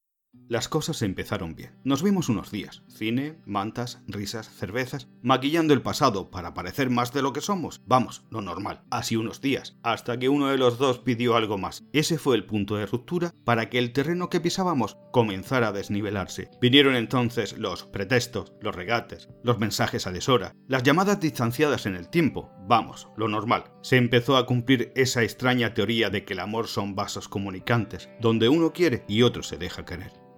Sprechprobe: eLearning (Muttersprache):
I am an active professional speaker, native in Spanish, with my own studio.
Audiolibro La triste historia de tu cuerpo sobre el mio_0.mp3